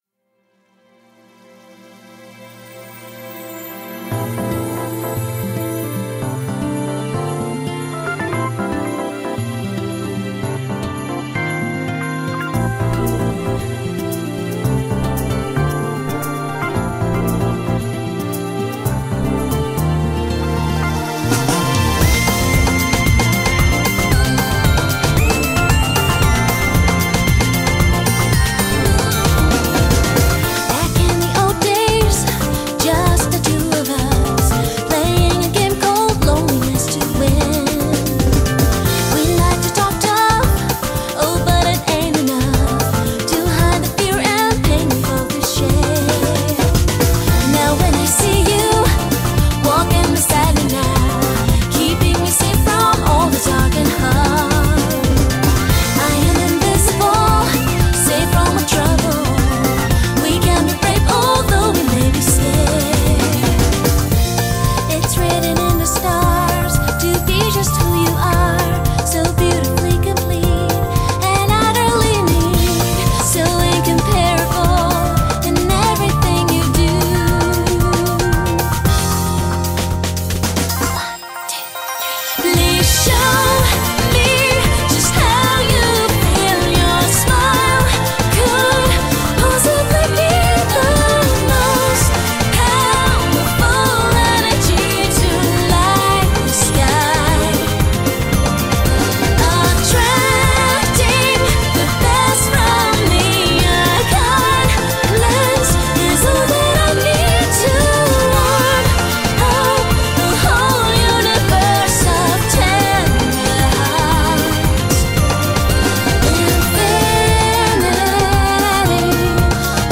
BPM114
Audio QualityPerfect (High Quality)
I'm decided to choose this chill and inspirational song.